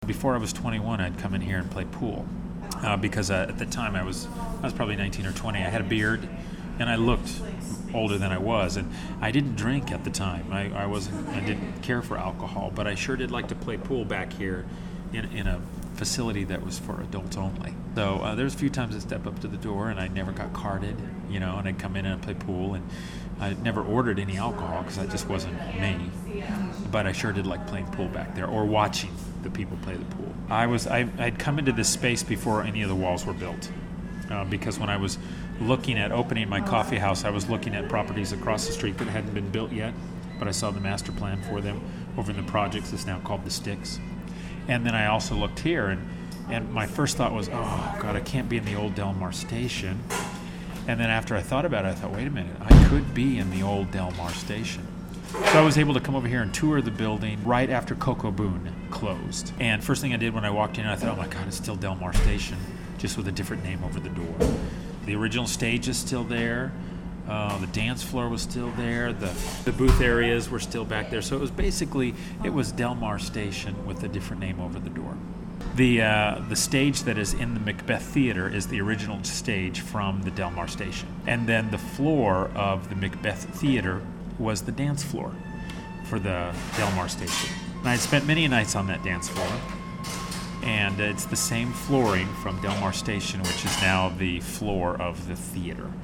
The location later became the site of JoStella Coffee Co.